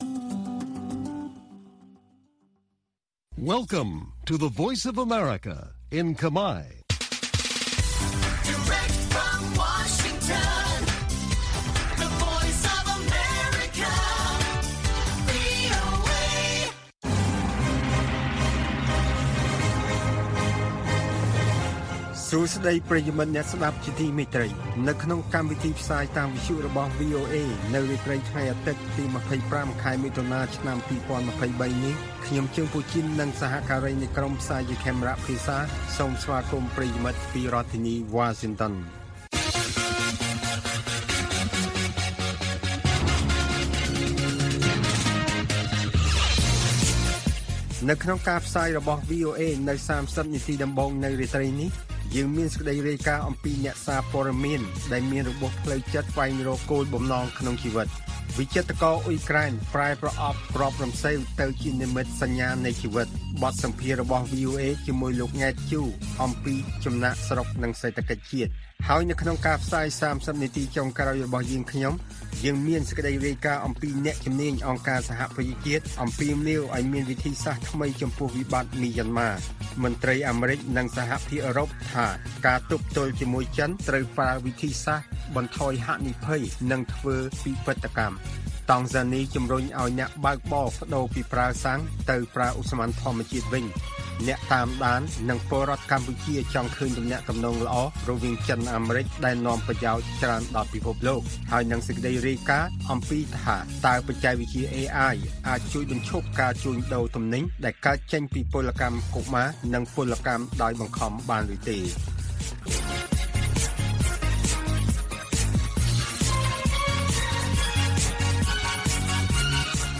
វិចិត្រករអ៊ុយក្រែនប្រែប្រអប់គ្រាប់រំសេវទៅជានិមិត្តសញ្ញានៃជីវិត។ បទសម្ភាសន៍ VOA